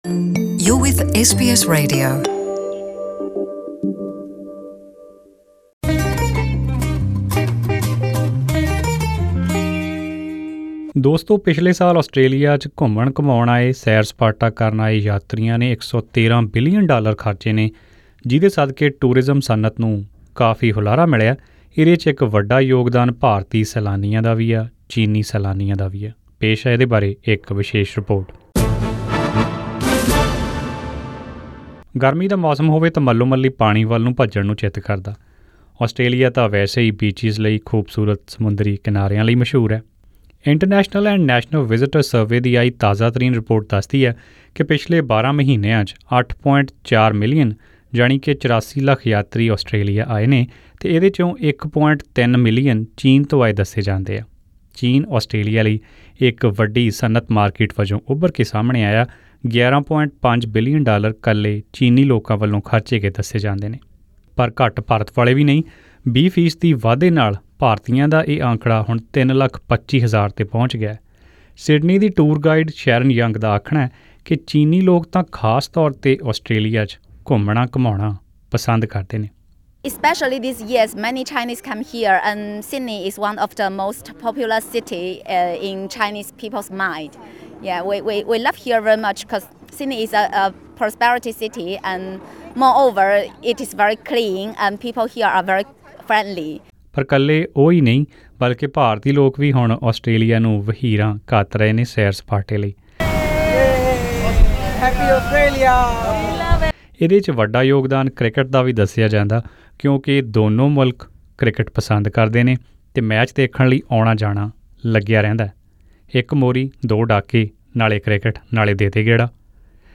Here is the full report: Related Article Indian and Chinese tourists are driving Australia's tourism boom Share